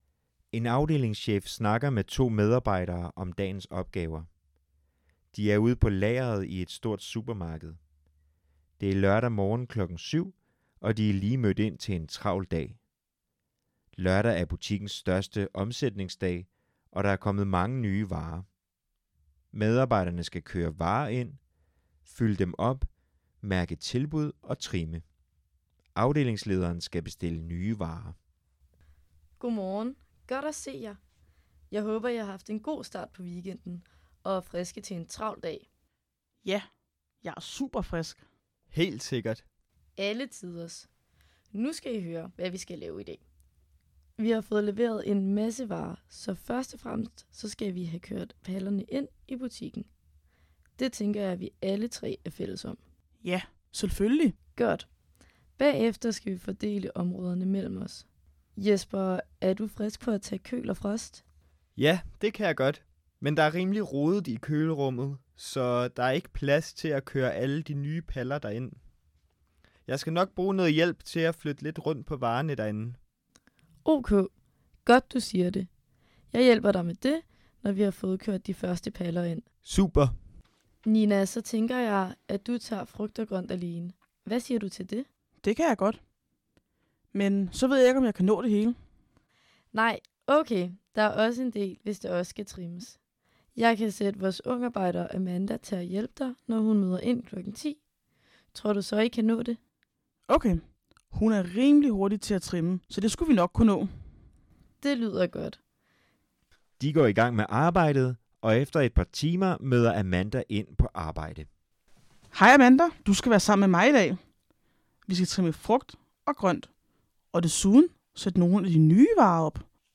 En afdelingschef snakker med to medarbejdere om dagens opgaver. De er ude på lageret i et stort supermarked.